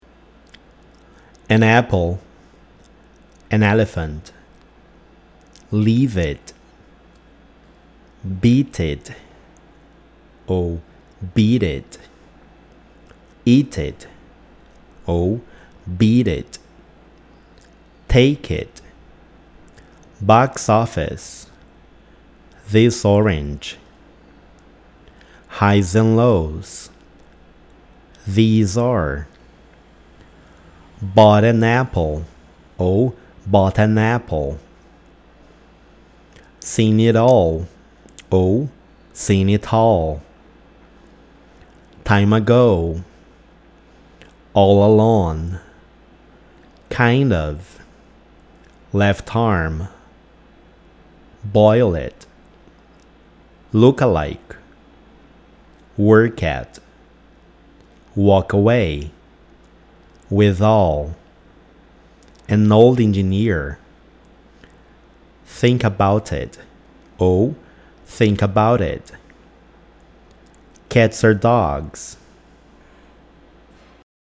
Quando uma palavra terminar com um som consonantal e a palavra a seguir começar com um som vocálico, as duas palavras serão pronunciadas como uma só.